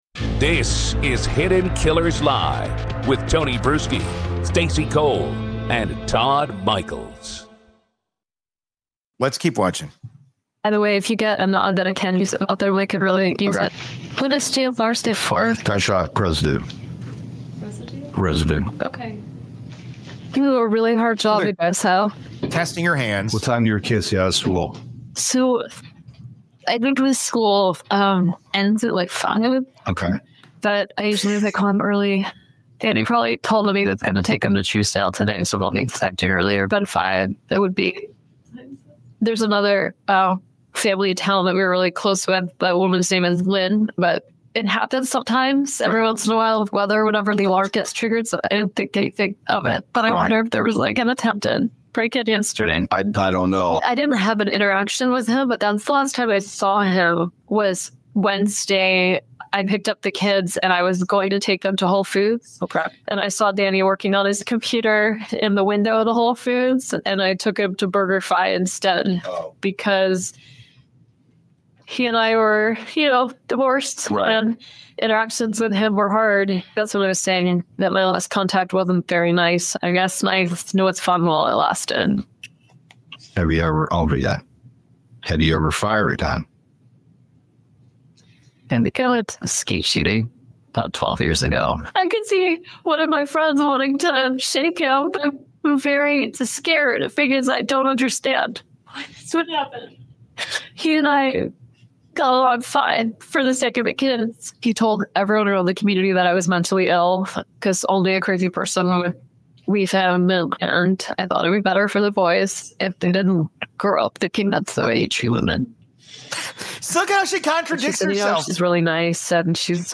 The team points out how her tone shifts depending on the question — at times defiant, at times almost childlike.